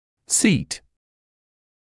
[siːt][сиːт]фиксировать, сажать на место, закреплять; усаживать, сажать